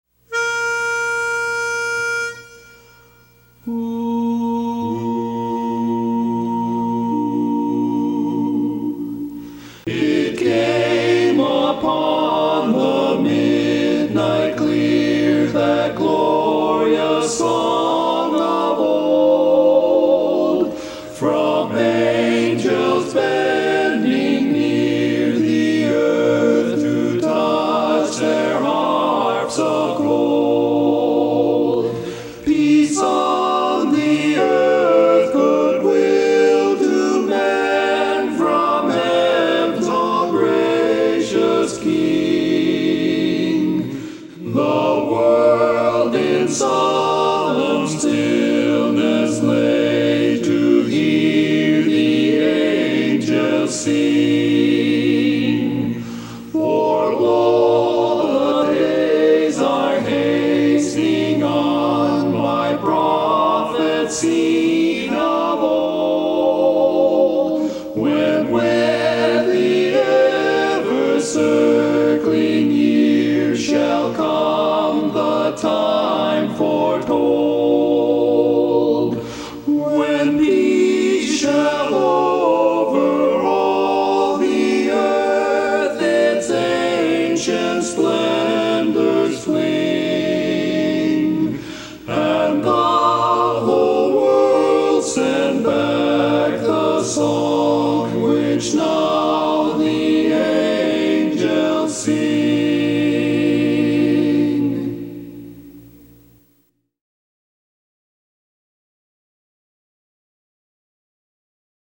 Barbershop
Lead